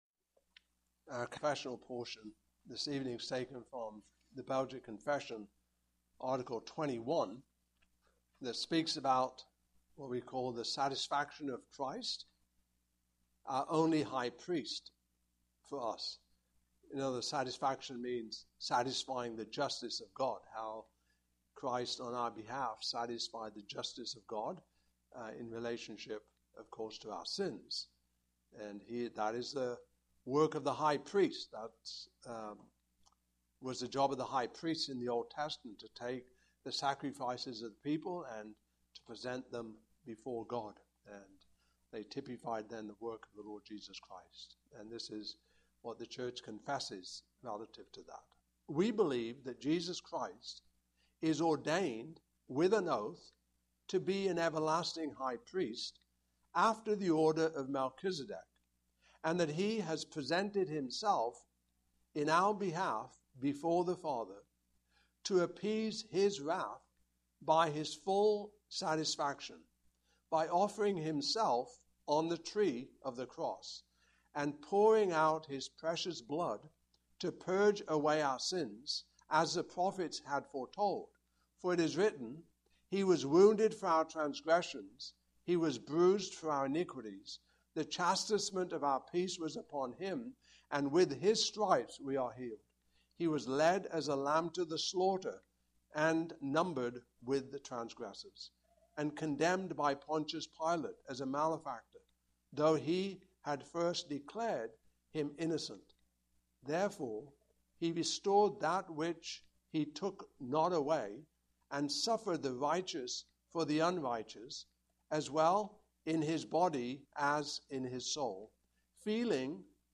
Belgic Confession 2025 Passage: Hebrews 10:1-14 Service Type: Evening Service Topics